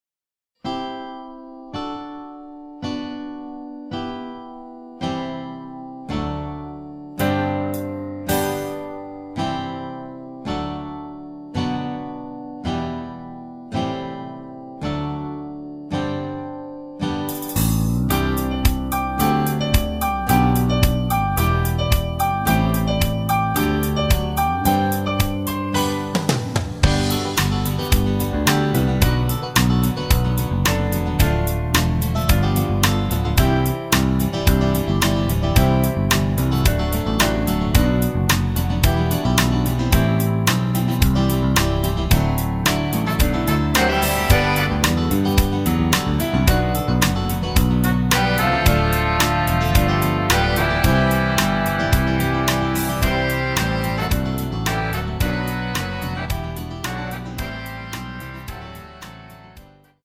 전주 없이 노래 들어가는 곡이라 전주 만들어 놓았습니다.
8초쯤 하이햇 소리 끝나고 노래 시작 하시면 됩니다.
앞부분30초, 뒷부분30초씩 편집해서 올려 드리고 있습니다.